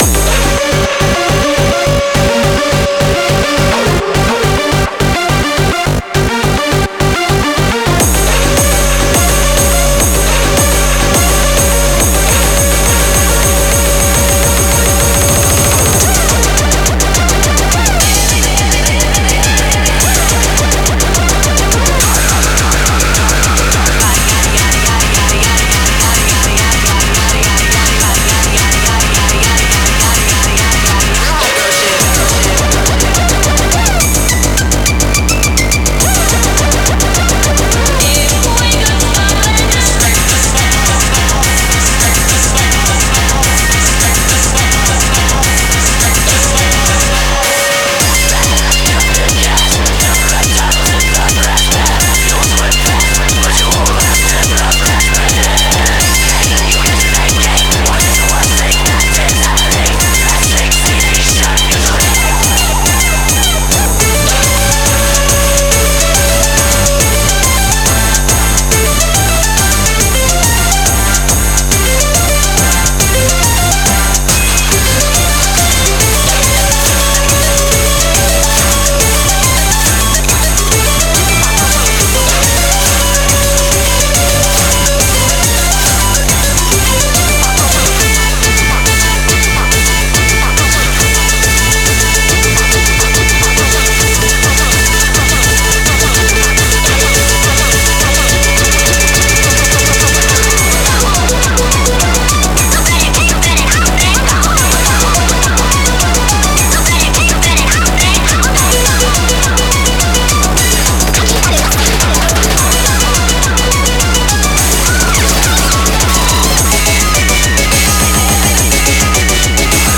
BPM210
Audio QualityPerfect (High Quality)
-Indian metal